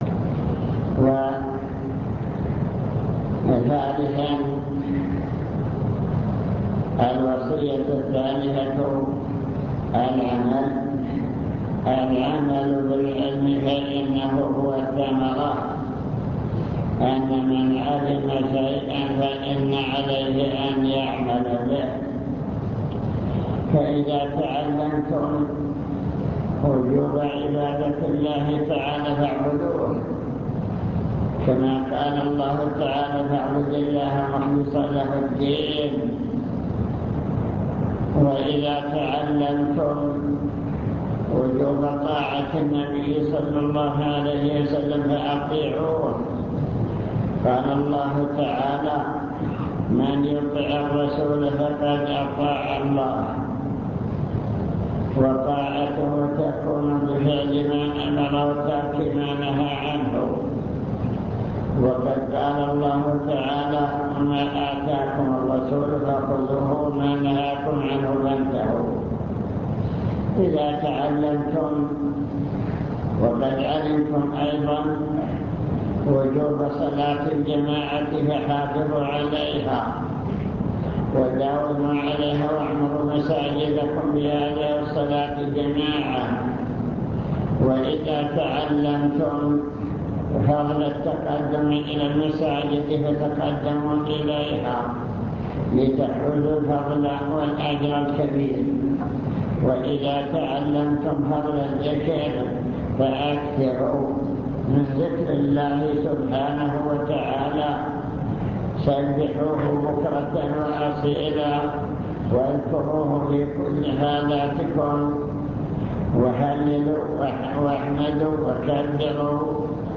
المكتبة الصوتية  تسجيلات - لقاءات  لقاء مفتوح مع الإجابة على الأسئلة